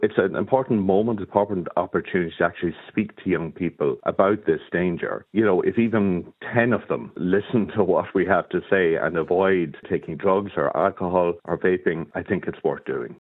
Bishop Michael Router, of the Irish Bishops’ Drugs Initiative, says protecting children’s health is the core aim of ‘The Pledge’: